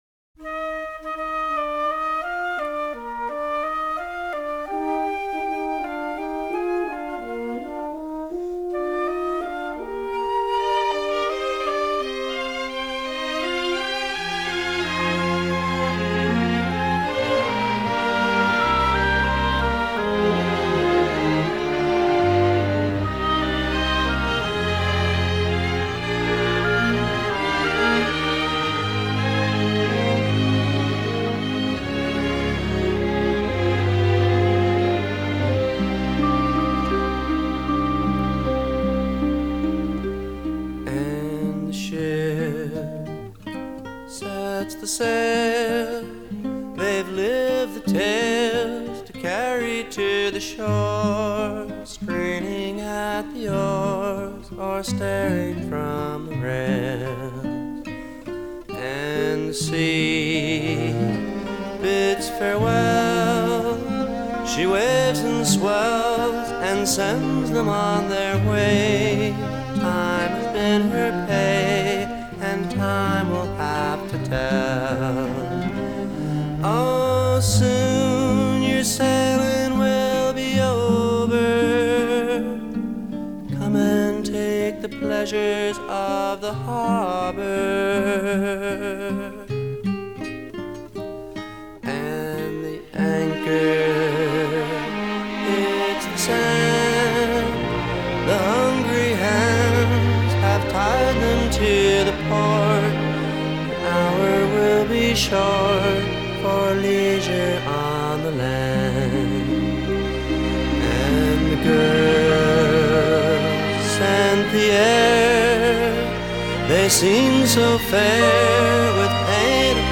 elegiac